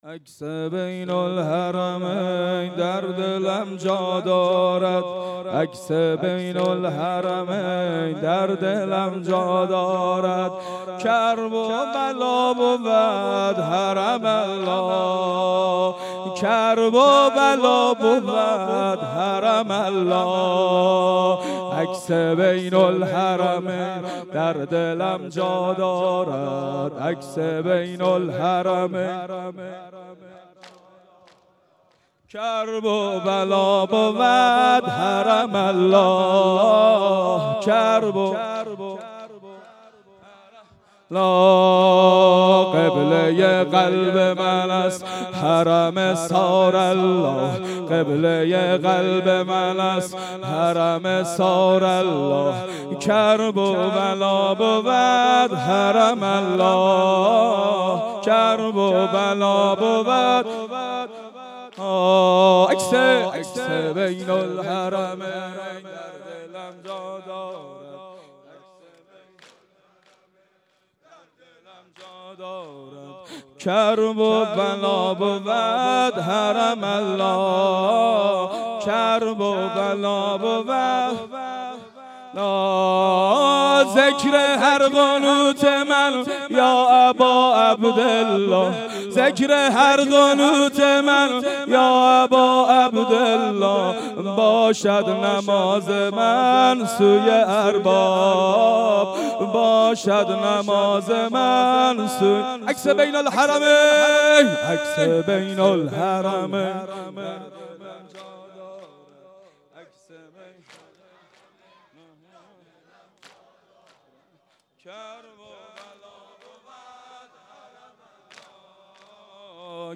نوحه خوانی